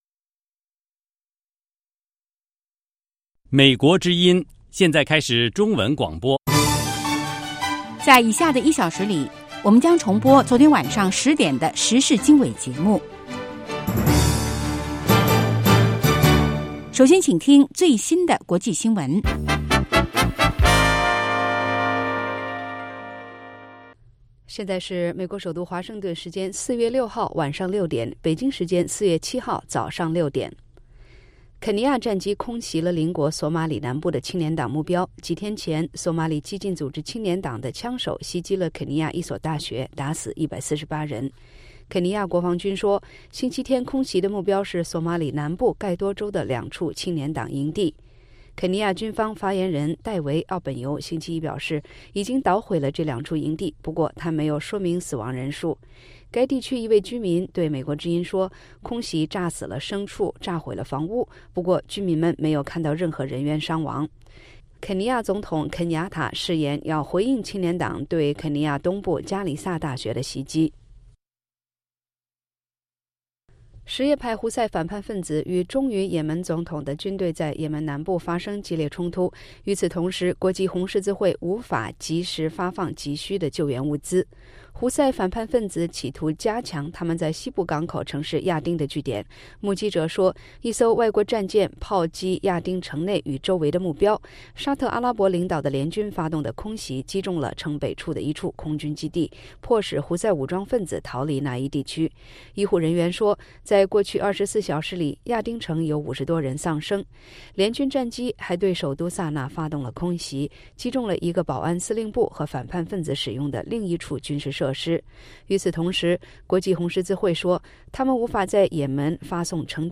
北京时间早上6-7点广播节目 这个小时我们播报最新国际新闻，并重播前一天晚上10-11点的时事经纬节目。